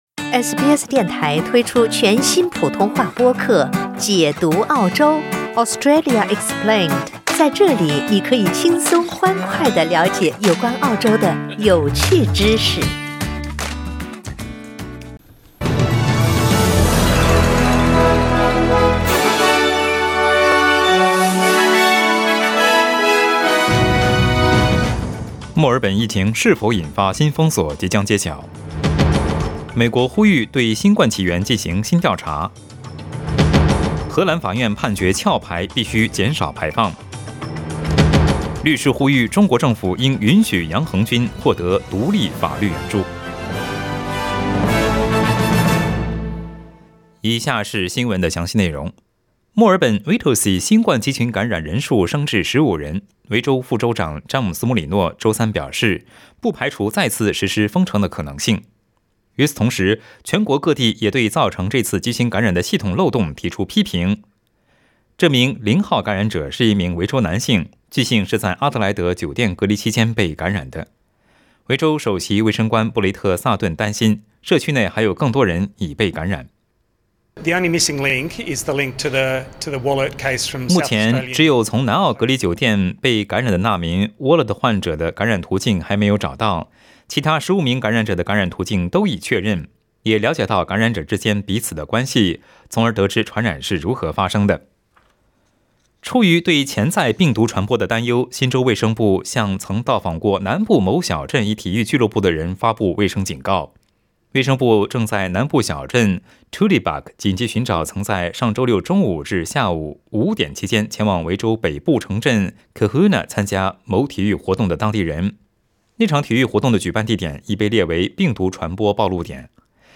SBS Mandarin morning news Source: Getty Images